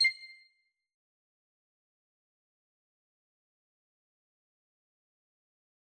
obsydianx-interface-sfx-pack-1
back_style_4_007.wav